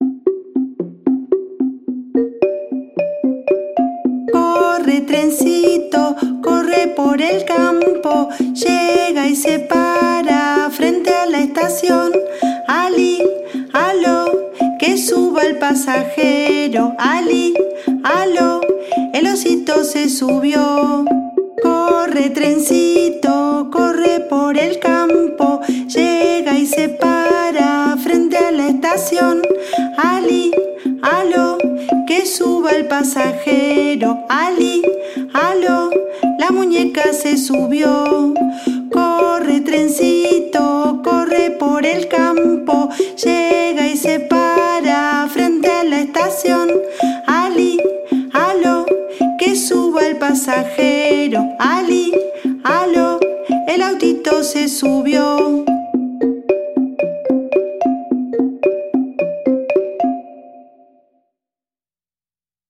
Paratocar con instrumentos